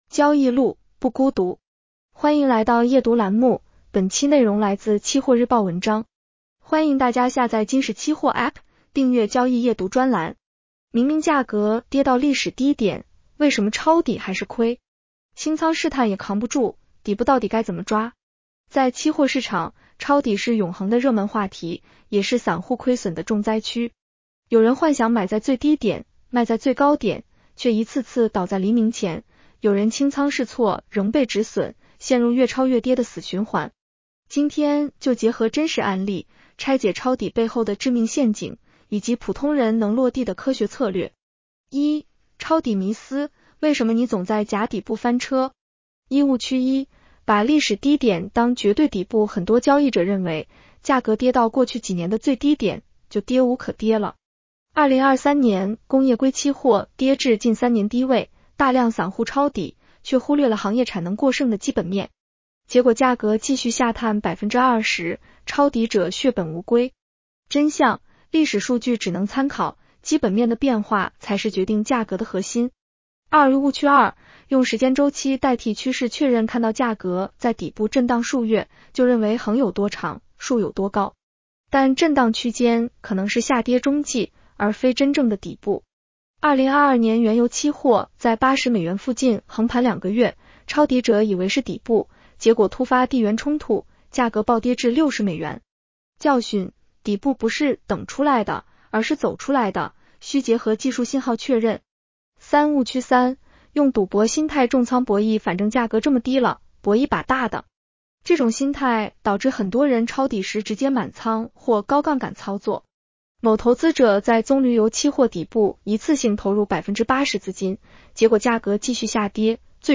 【期货交易夜读音频版】
女声普通话版 下载mp3